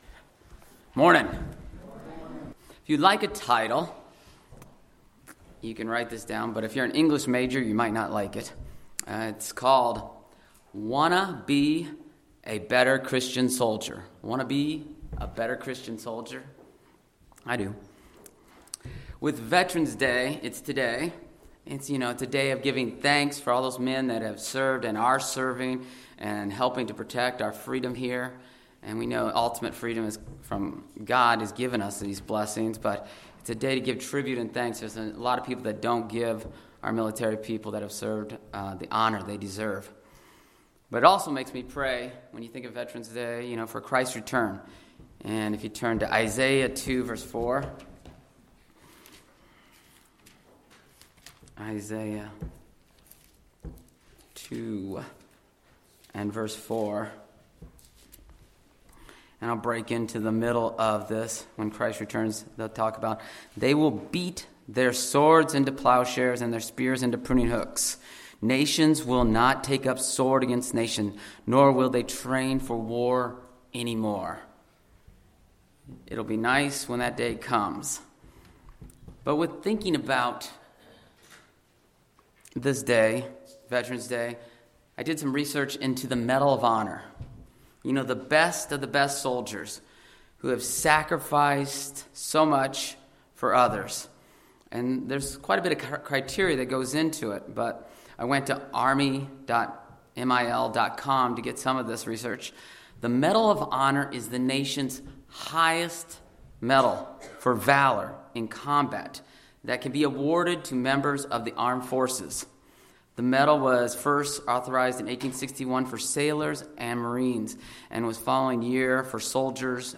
How does this soldier compare to us as Christian soldiers? This sermon goes through four characteristics of a good Christian soldier using an example of one from the Bible.